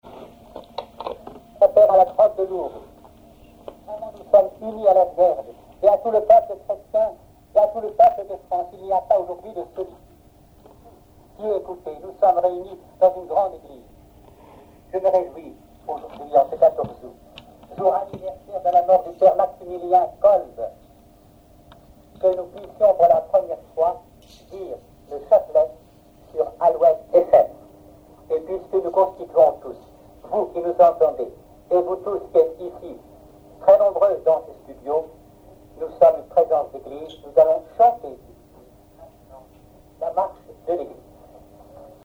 prière(s)
Catégorie Témoignage